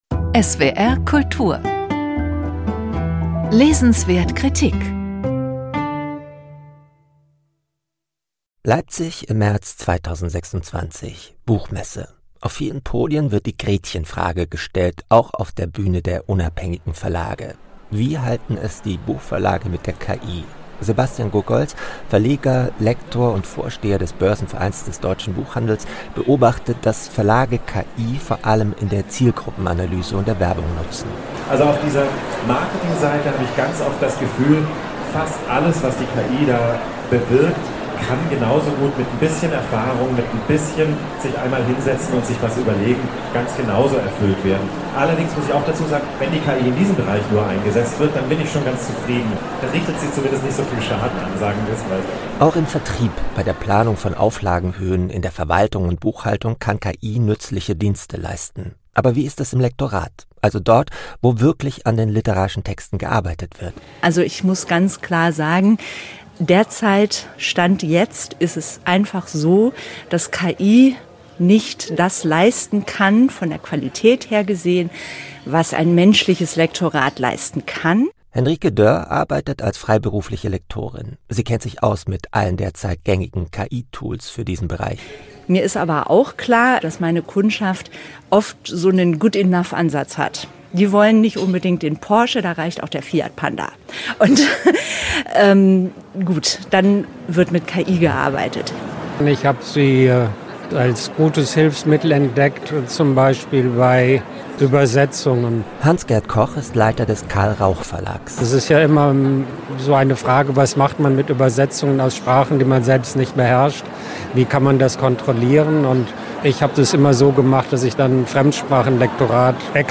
Reportage von